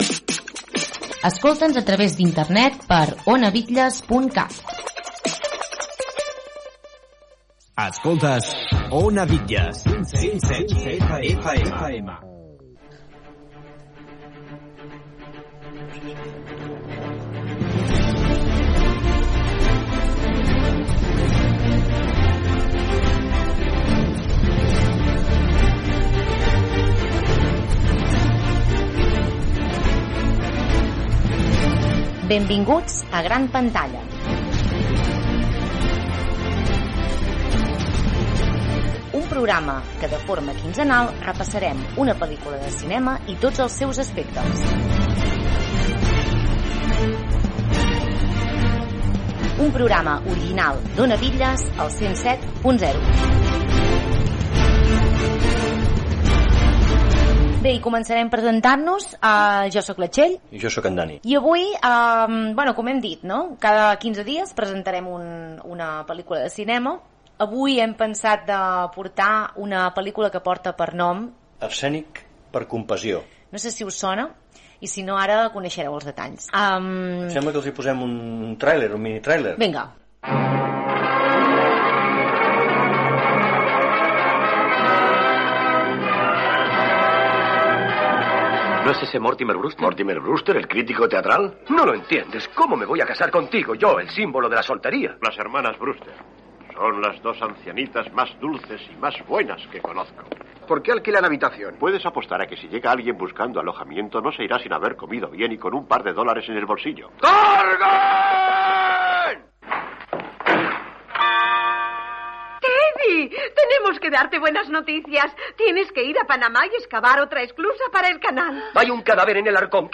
Indicatiu de l'emissora, sintonia, presentació i programa dedicat a la pel·lícula "Arsènic per compassió", amb petites escenes de l'obra i peculiaritats del director, actors, etc....